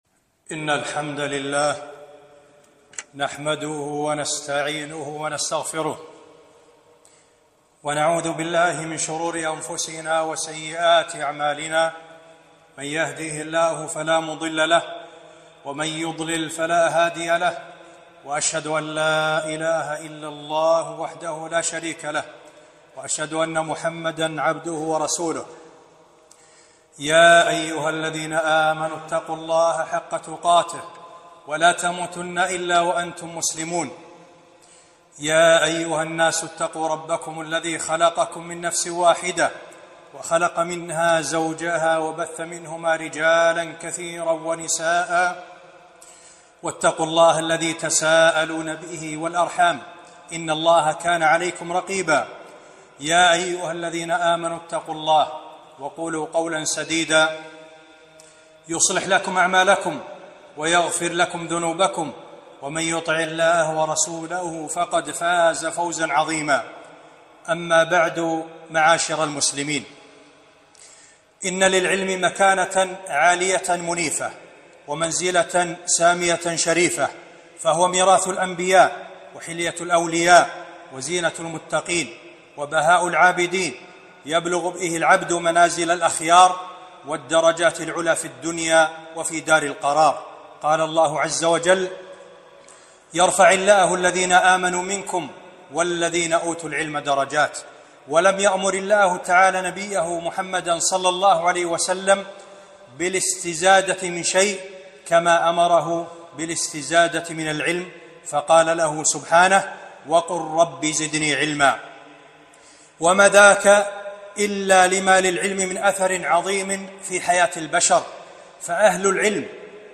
خطبة - من آداب المعلم والمتعلم